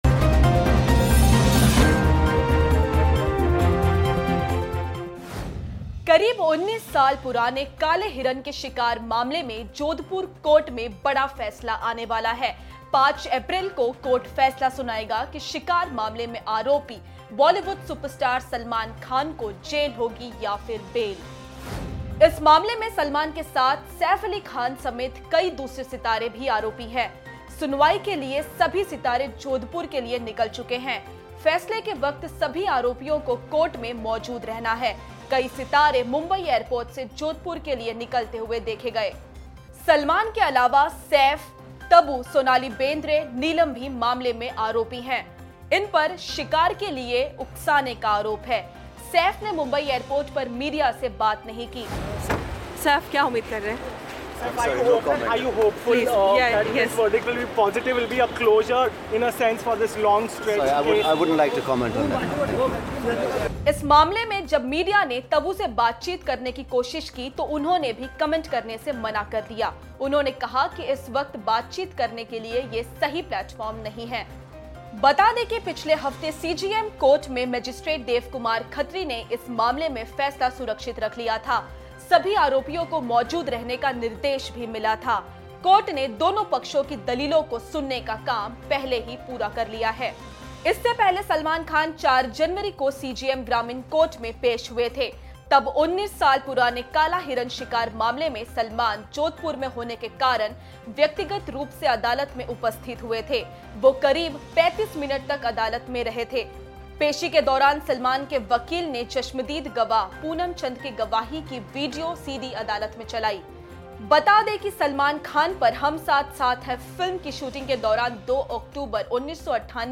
News Report / 19 साल बाद सलमान खान पर आएगा फैसला